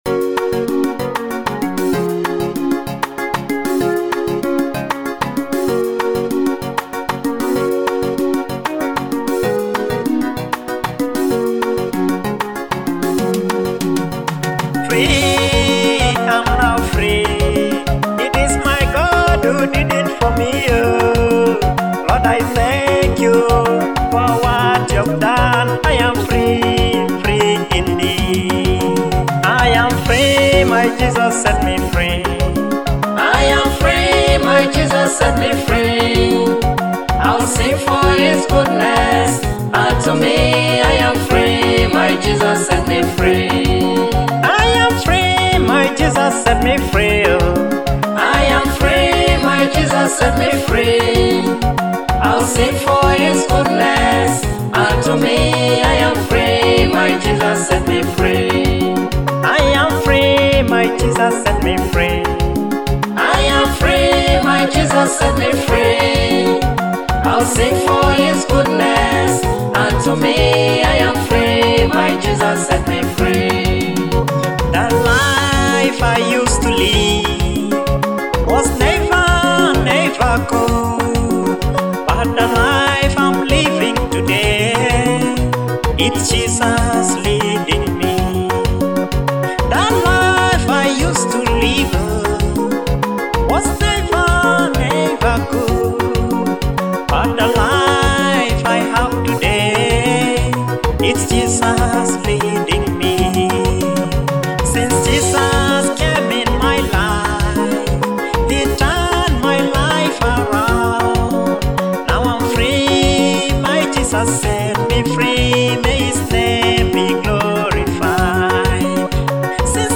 African Gospel anthem
Experience the uplifting sound of African gospel